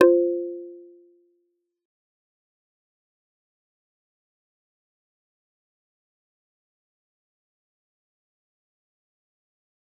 G_Kalimba-F4-f.wav